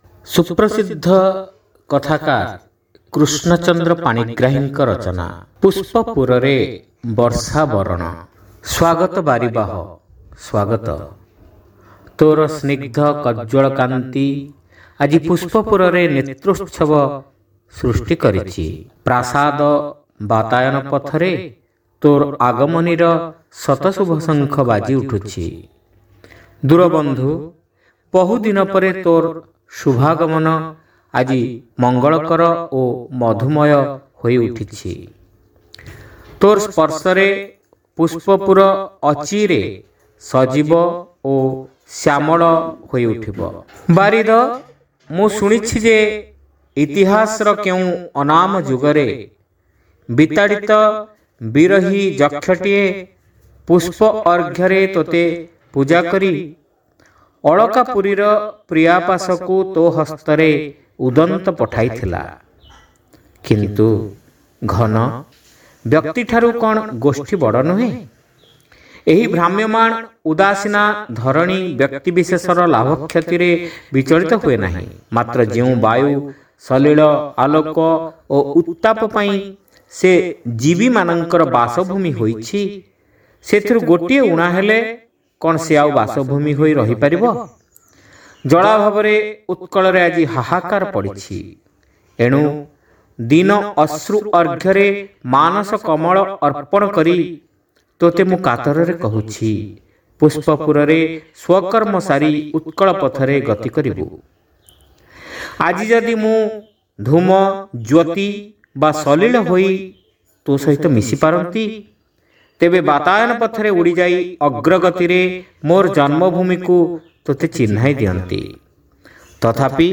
ଶ୍ରାବ୍ୟ ଗଳ୍ପ : ପୁଷ୍ପପୁରରେ ବର୍ଷାବରଣ